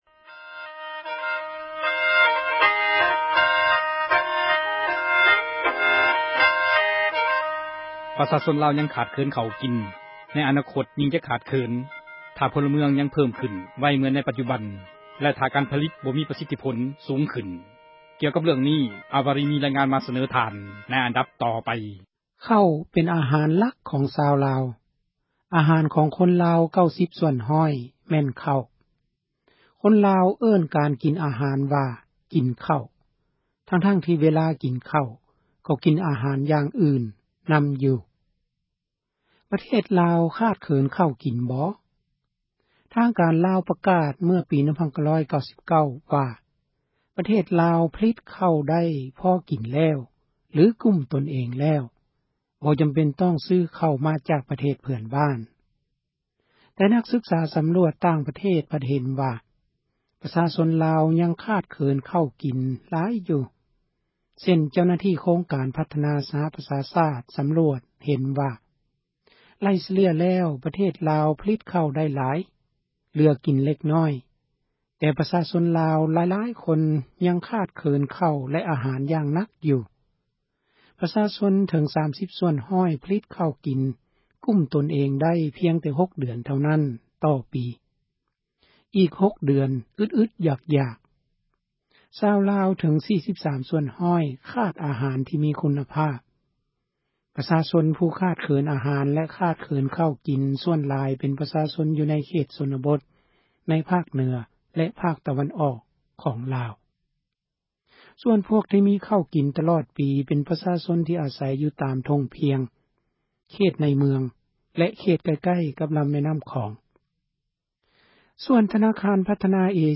ມີຣາຍງານມາສເນີທ່ານ ໃນອັນດັບຕໍ່ໄປ.